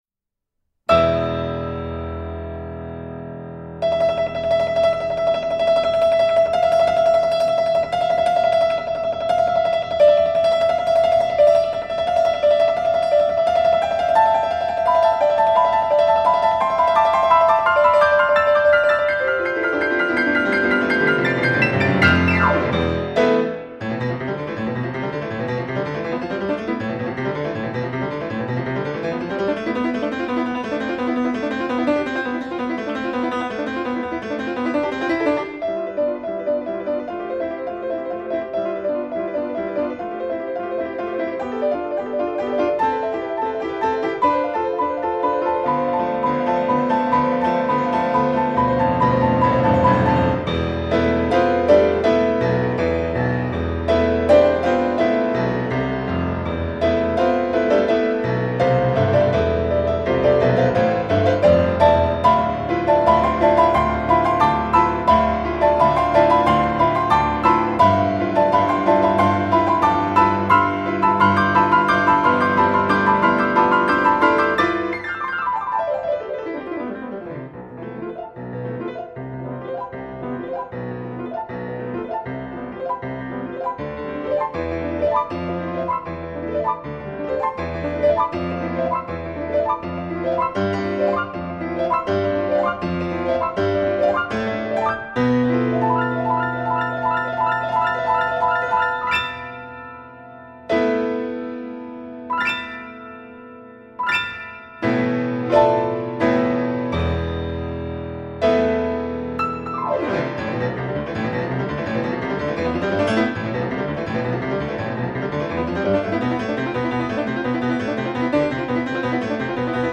klavieres